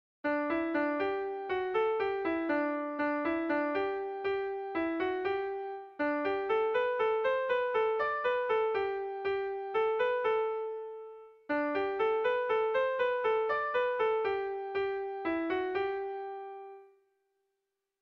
Kontakizunezkoa
A1A2BB